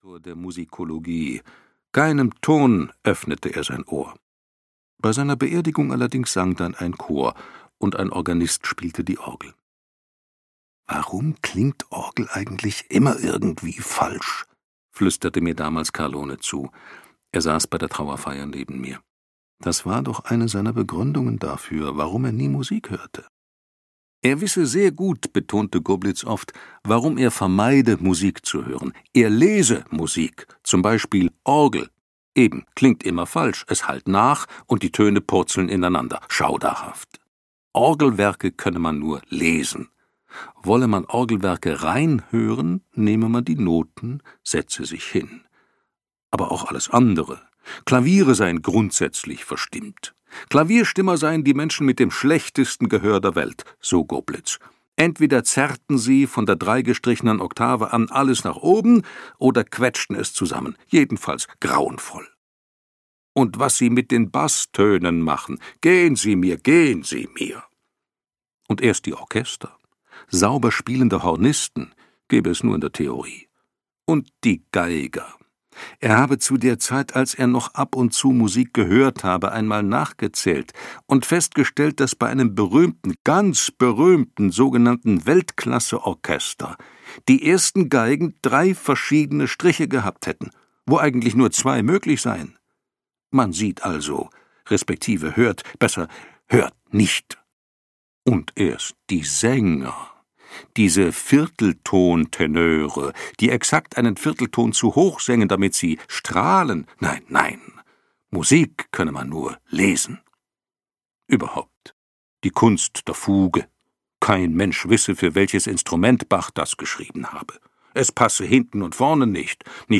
Der Meister - Herbert Rosendorfer - E-Book + Hörbuch